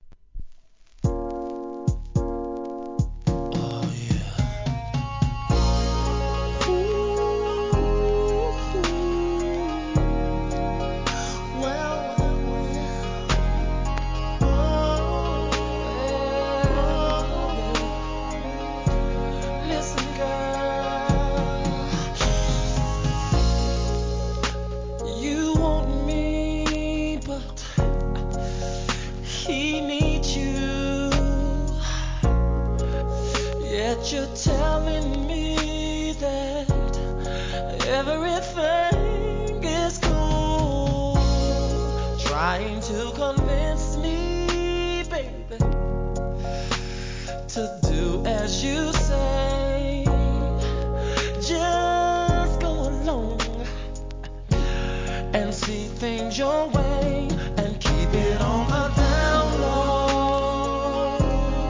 HIP HOP/R&B
メロ～、エロ～なクラシック揃い！！